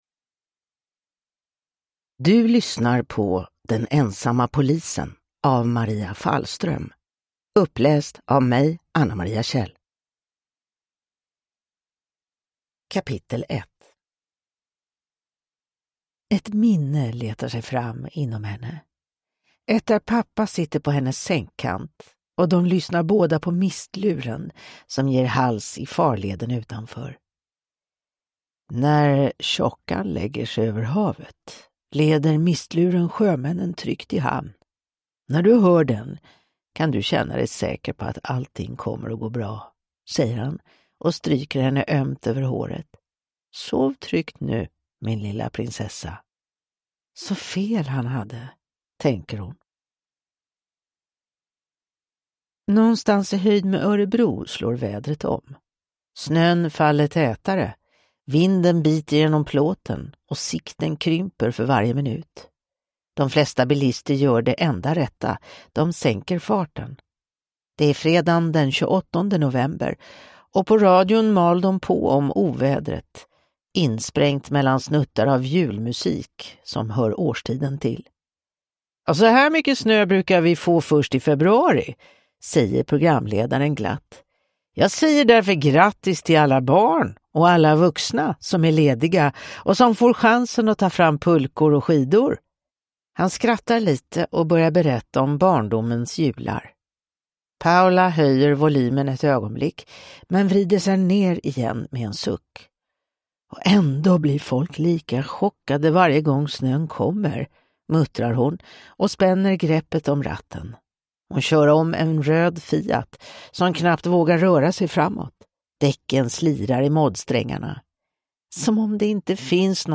Den ensamma polisen (HELA ROMANEN) (ljudbok) av Maria Fallström